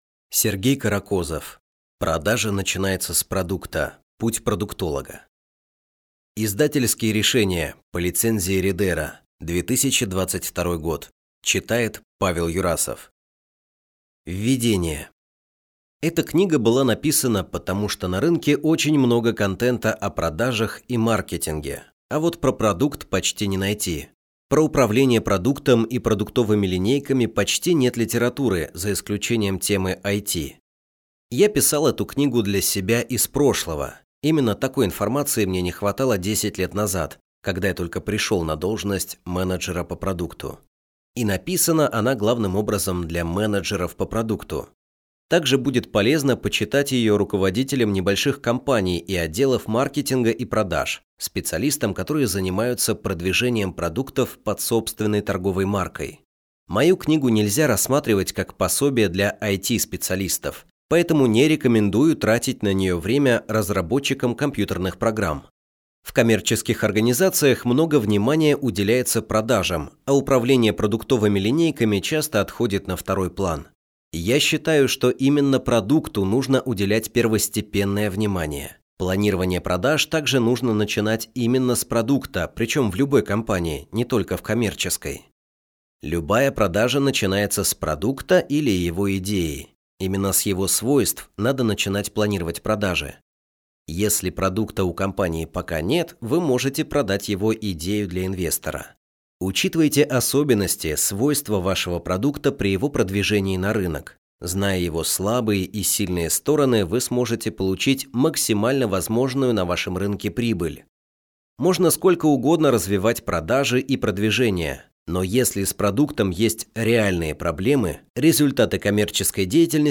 Аудиокнига Продажа начинается с продукта. Путь продуктолога | Библиотека аудиокниг